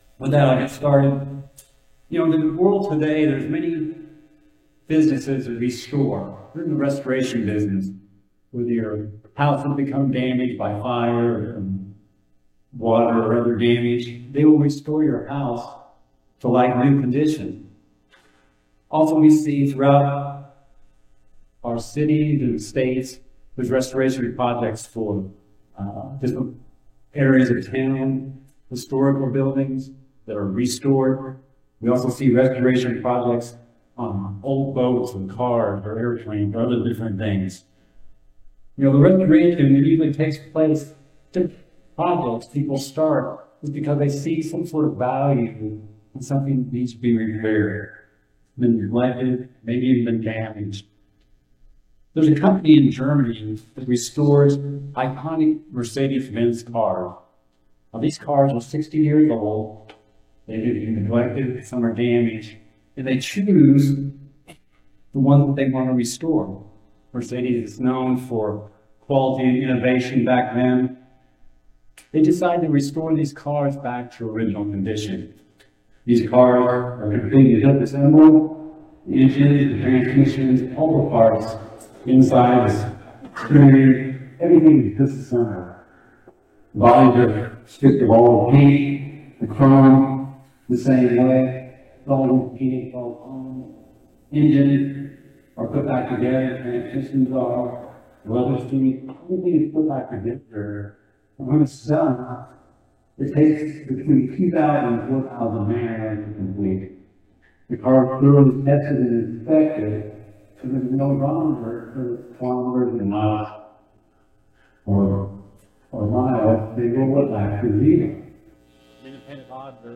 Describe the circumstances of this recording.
Given in Rapid City, South Dakota